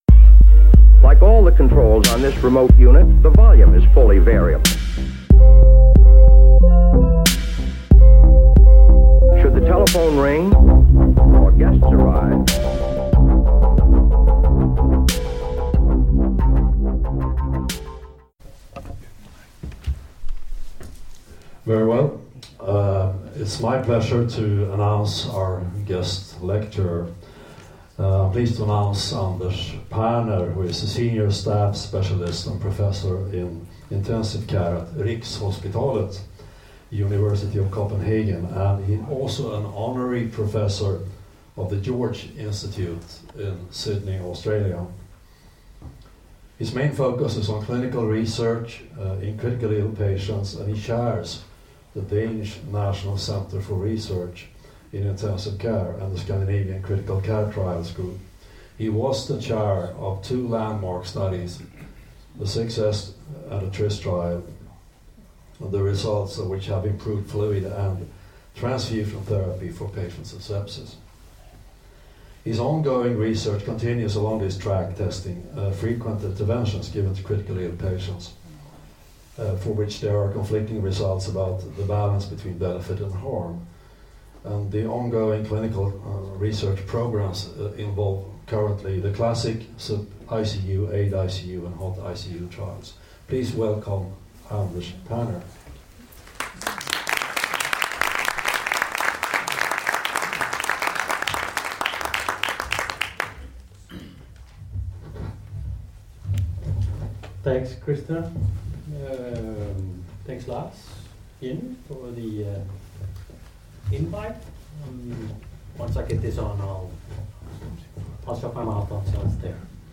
Föreläsningen hölls under Regionmötet för anestesi och intensivvård i SLL på Danderyds sjukhus i april 2017.